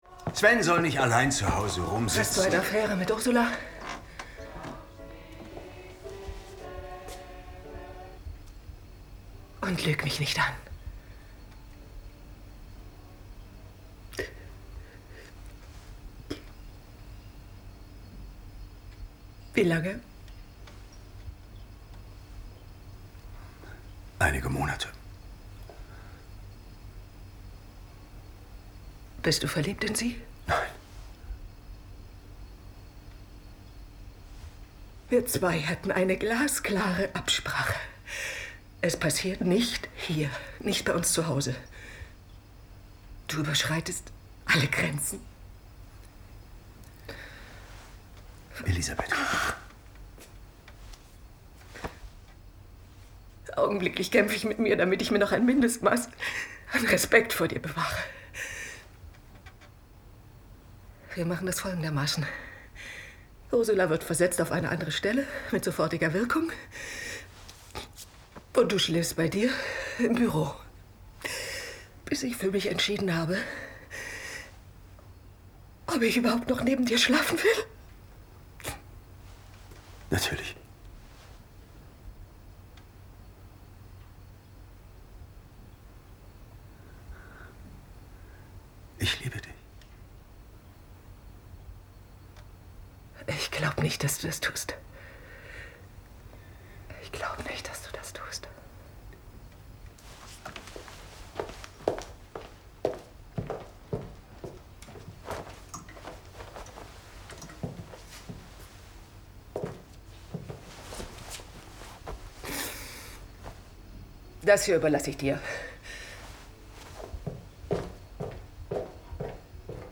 Synchron / Serienhauptrolle